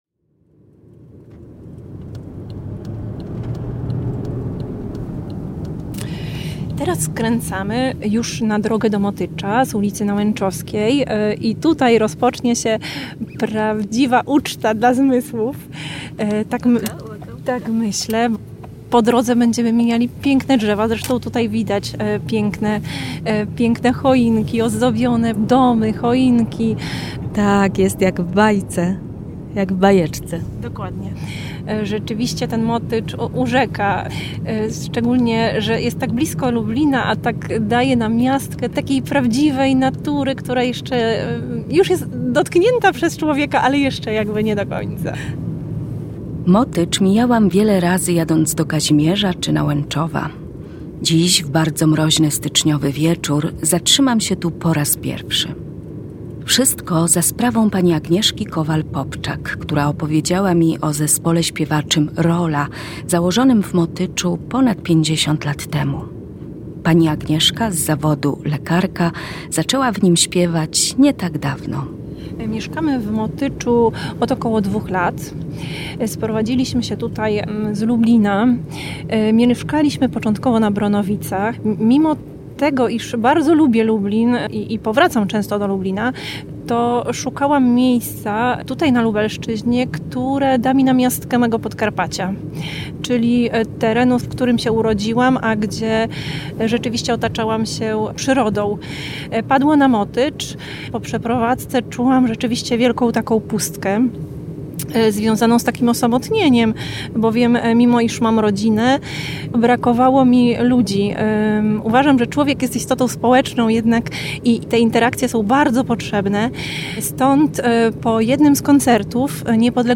Reportaż jest opowieścią o małej wspólnocie ludzi w różnym wieku, z których każdy ma inna i bardzo osobistą motywację, by śpiewać w Zespole.